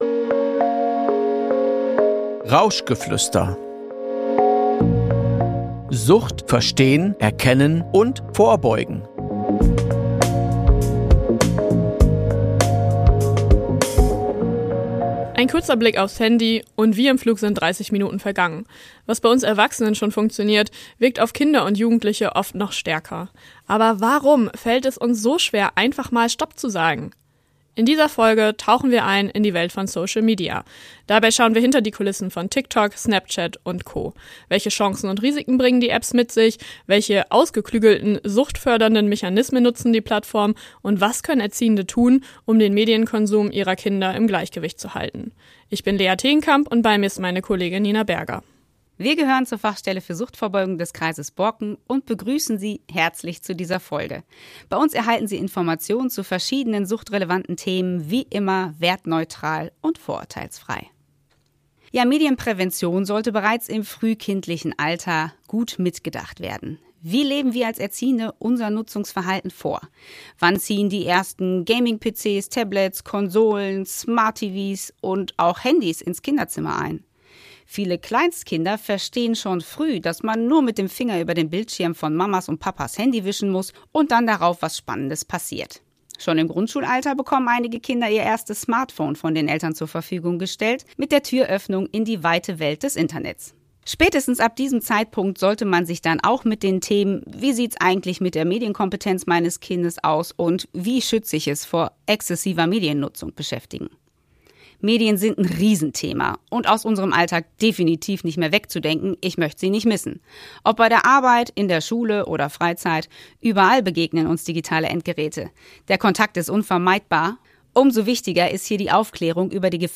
Sie beleuchten die Chancen und Gefahren, sprechen über die suchtfördernden Mechanismen hinter Plattformen wie TikTok und Snapchat, und zeigen auf, wie Erziehende präventiv handeln können. Ein Gespräch, das informiert, einordnet und stärkt – für alle, die mit jungen Menschen im Austausch bleiben wollen.